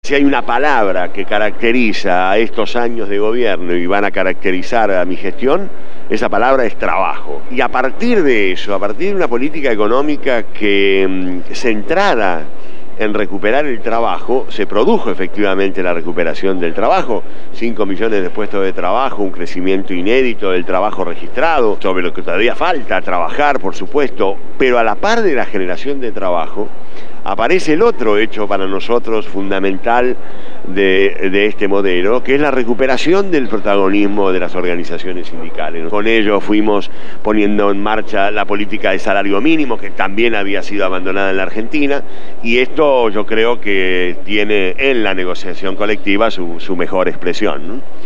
Carlos Tomada participó de la Jornada «Ciudadanía e Inclusión» que tuvo lugar en las calles Pedro de Mendoza y Almirante Brown, en el barrio de La Boca. Tomada hizo declaraciones en la transmisión especial que Radio Gráfica FM 89.3 llevó adelante para cubrir la jornada.